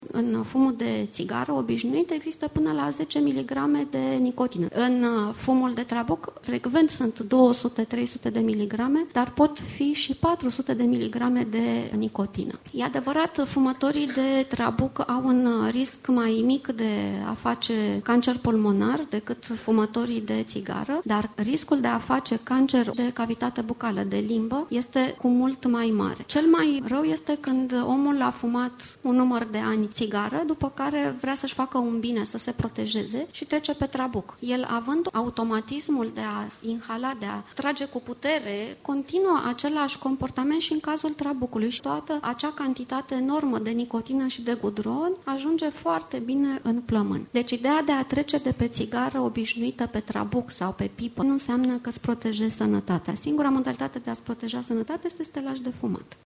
medicul pneumolog